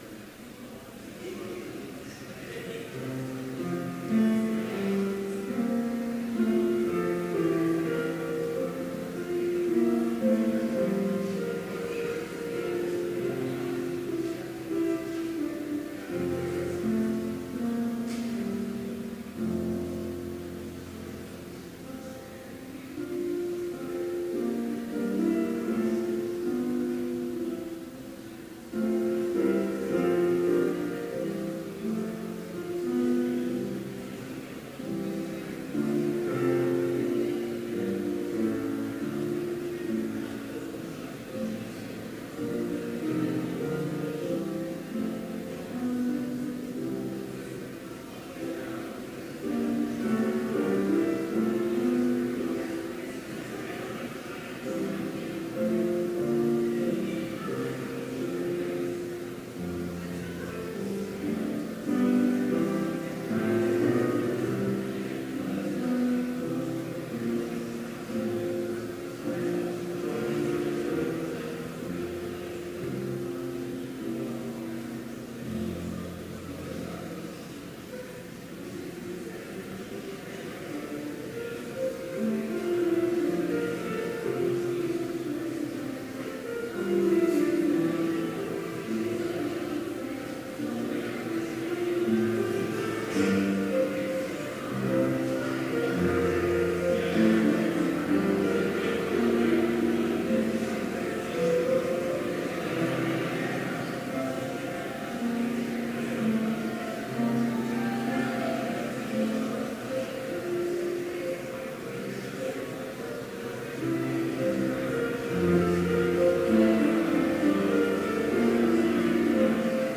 Chapel in Trinity Chapel, Bethany Lutheran College
Complete service audio for Chapel - September 28, 2018